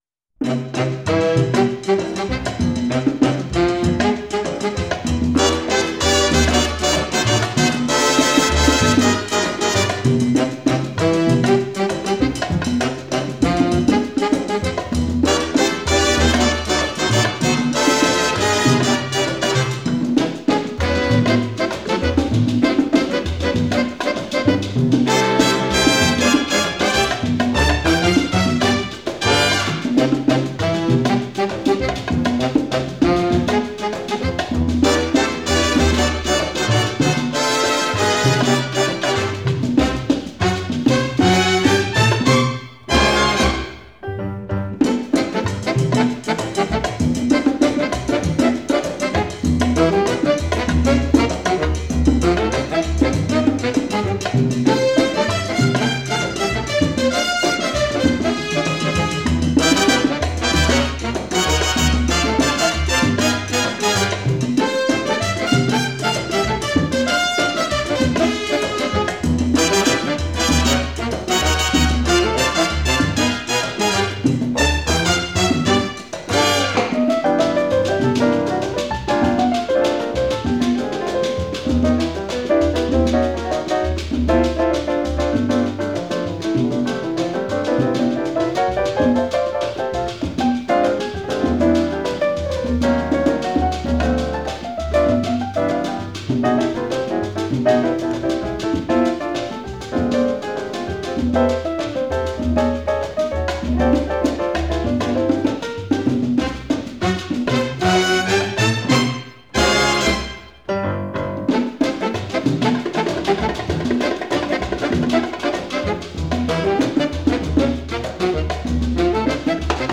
I love this mambo interpretation.
1927   Genre: Musical   Artist